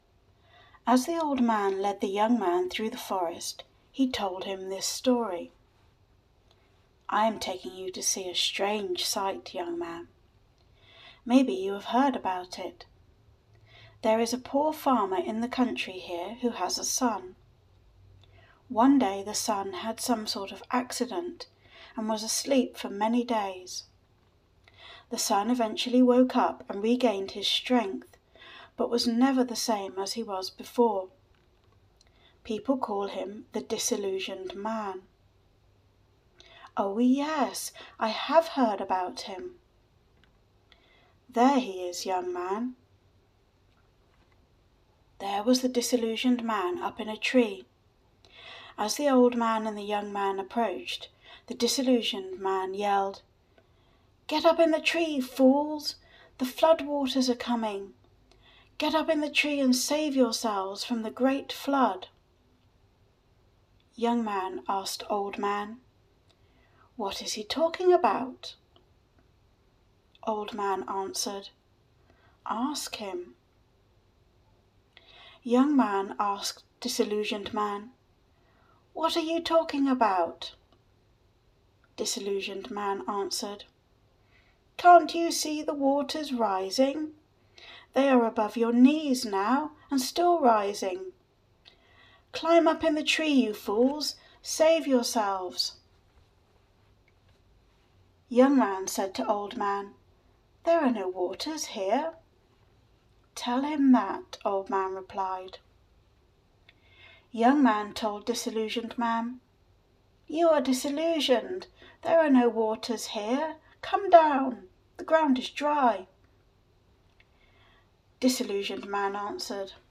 The Young Man and the Old Man - Audiobook